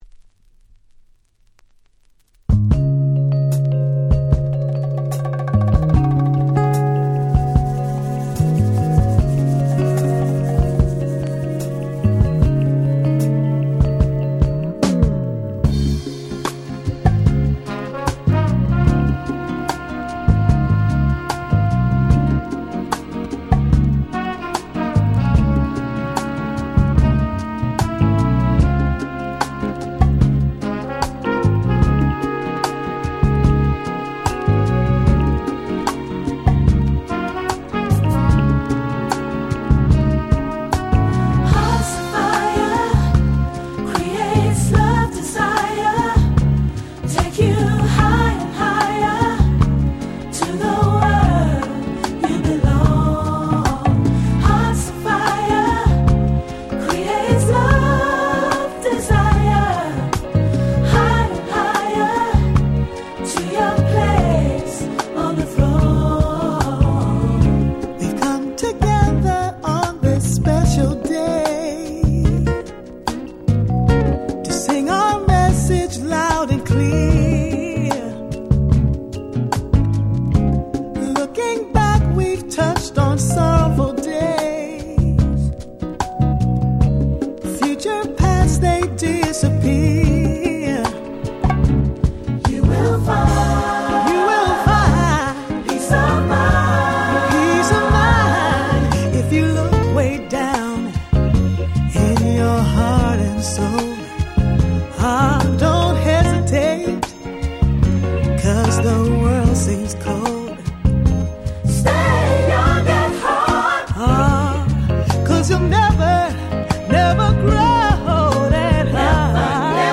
07' Very Nice Cover R&B !!